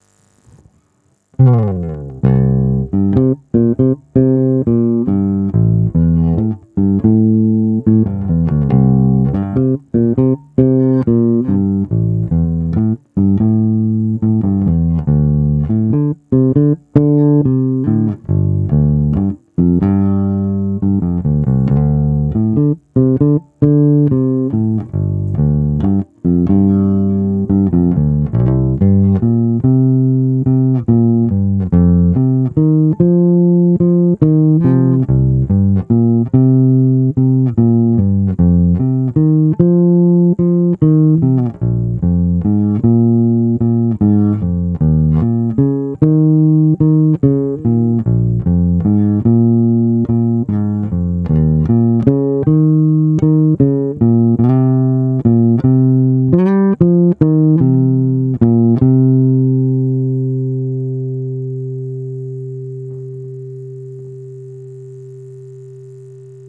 Nahrávky s hlazenkama D´addario chromes ECB81:
Hlazenky tonovka na půl